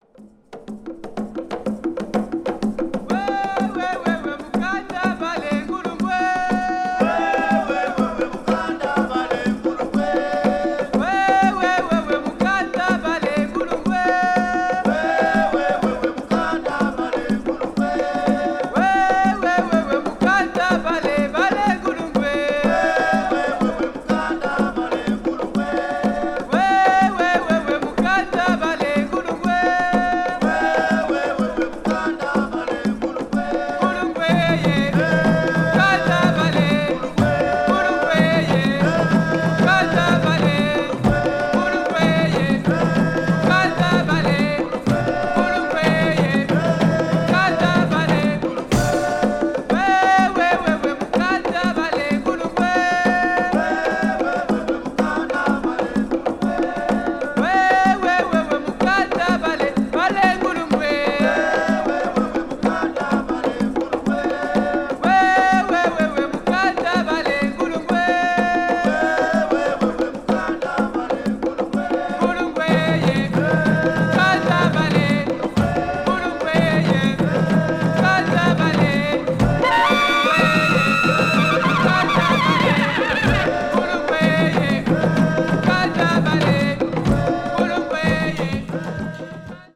the saxophone group
the African percussion group
african jazz   ethnic jazz   experimental jazz   free jazz